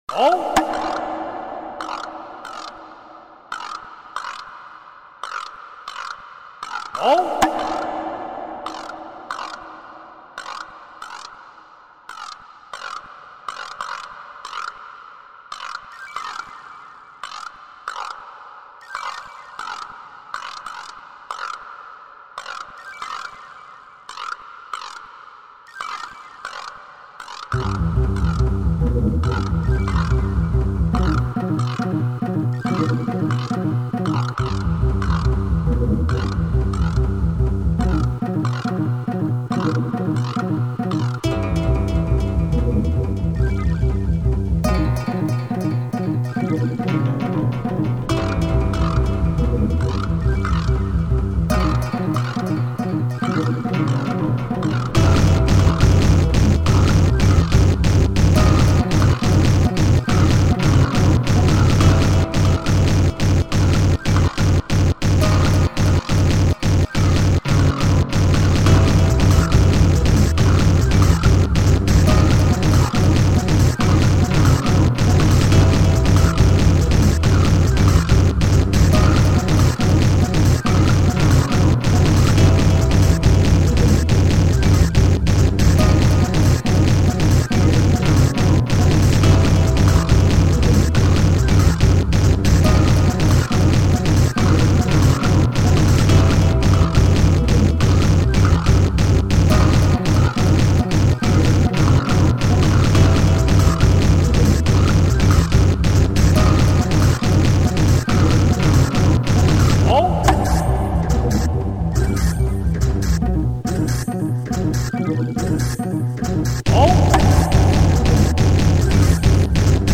File under: New Electronica
Funny and abrasive at the same time.